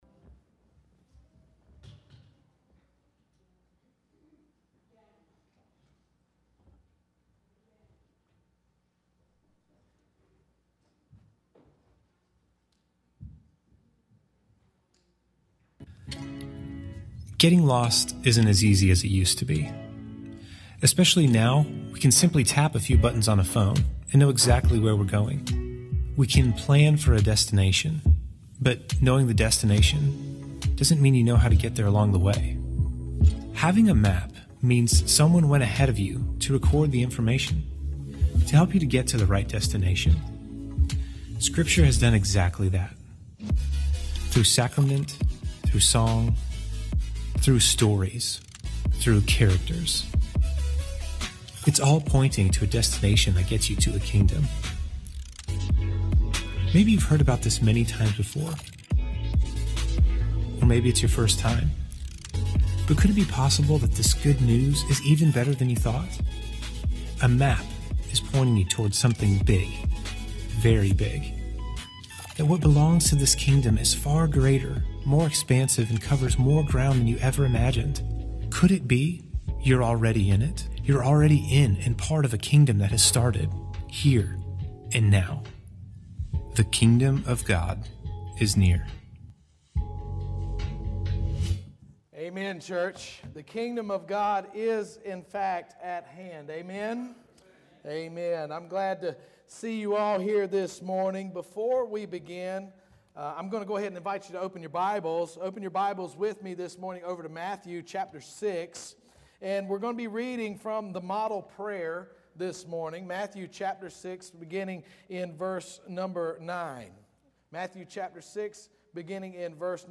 First Baptist Church Roanoke Rapids Online Sermons